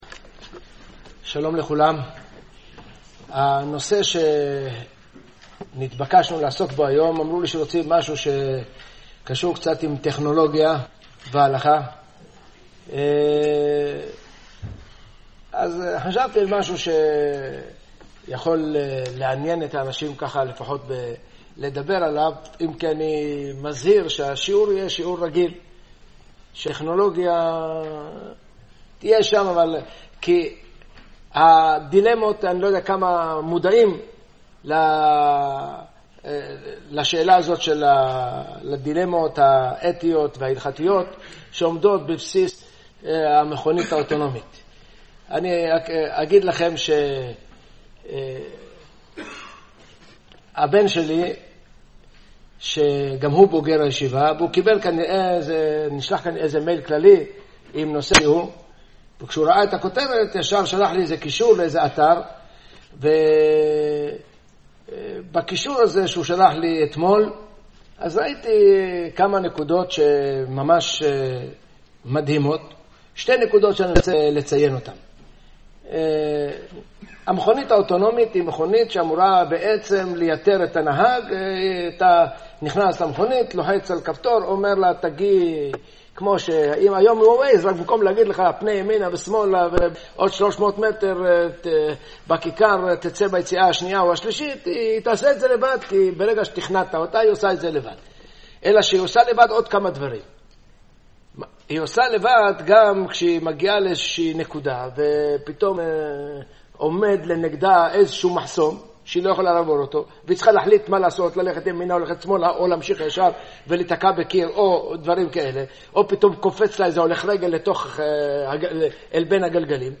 השיעור התקיים ביום שלישי יח במרחשוון תשעח (7.11.2017) בקהילת רמת מודיעים ע"ש גלנווד, חשמונאים